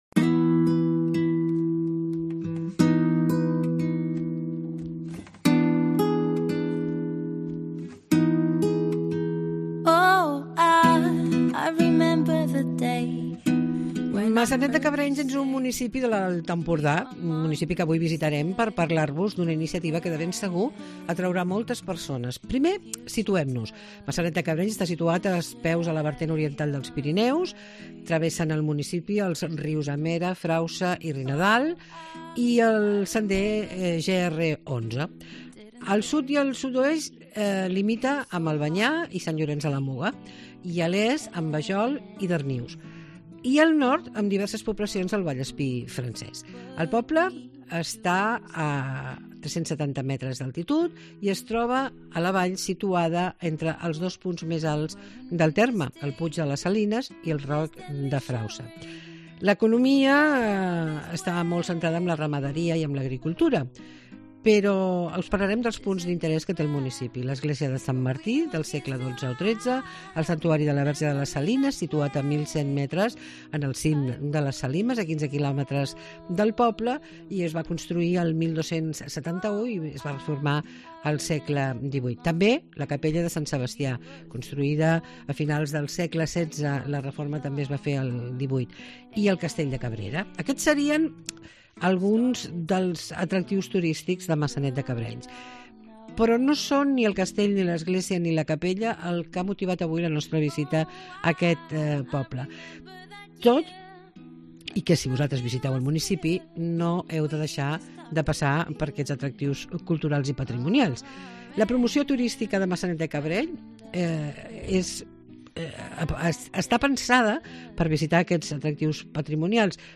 Coneix una destinació de “silenci”,escolta l'entrevista amb Mercè Bosch, alcaldessa de Maçanet de Cabrenys